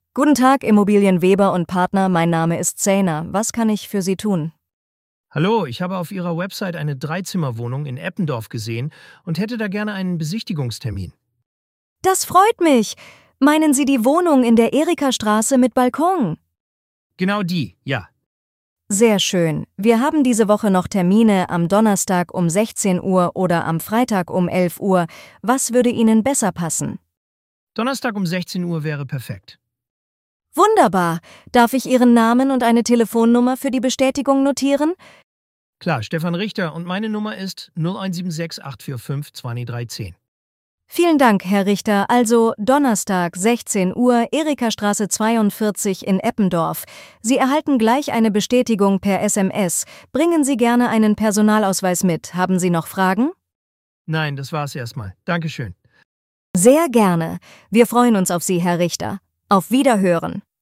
Wählen Sie Ihre Sprache und hören Sie echte KI-Gespräche.
voice-demo-realestate.mp3